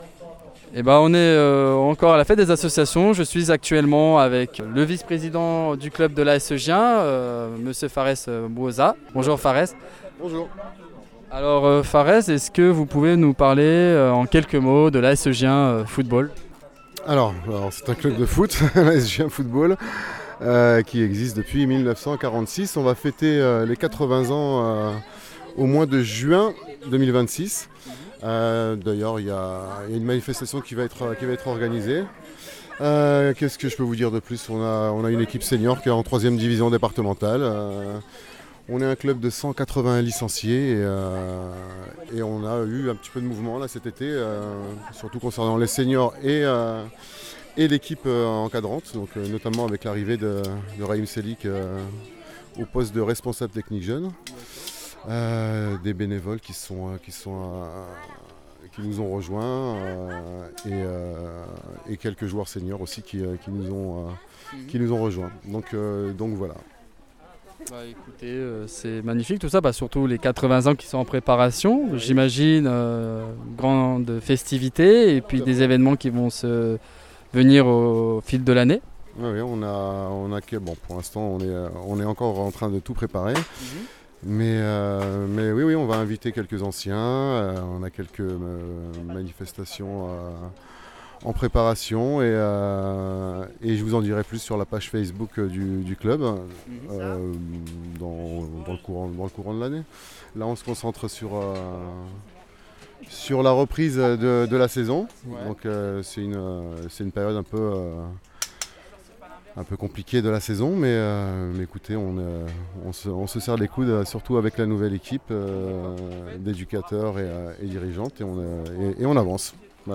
Fête des associations de Gien 2025 - AS Gien Football